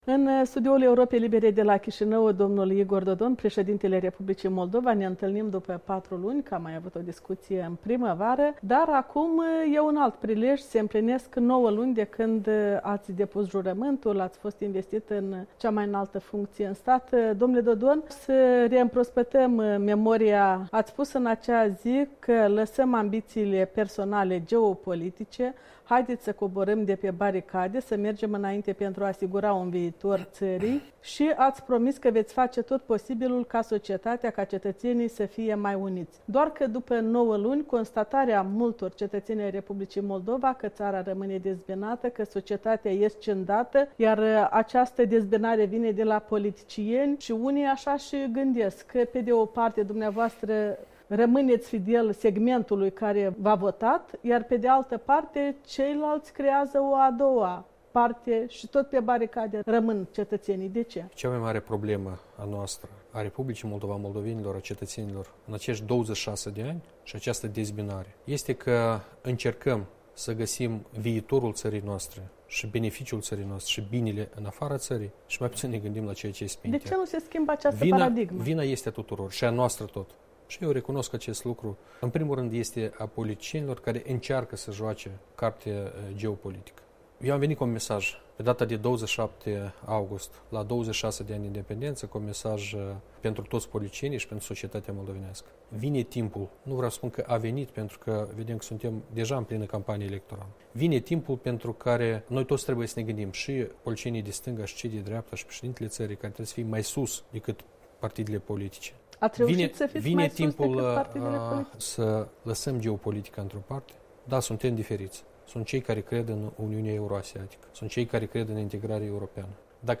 Interviu cu preşedintele Republicii Moldova Igor Dodon